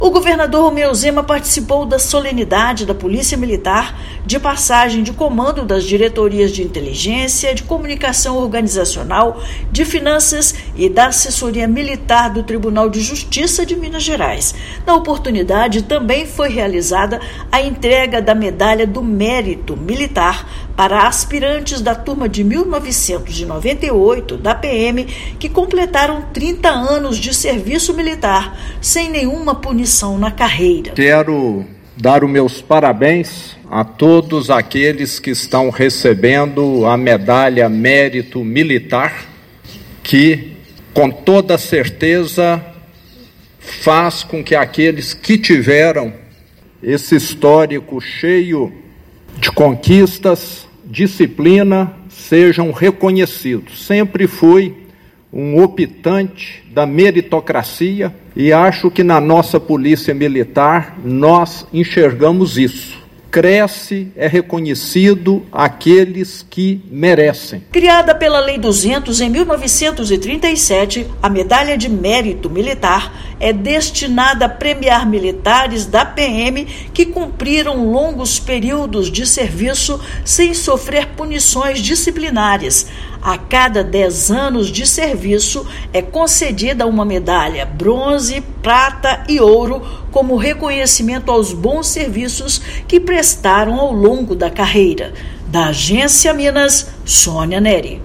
[RÁDIO] Governador participa da passagem de comando de diretorias da PMMG e da entrega da Medalha de Mérito Militar
Durante a solenidade, dez militares foram agraciados com a Medalha Grau Ouro como reconhecimento aos serviços prestados. Ouça matéria de rádio.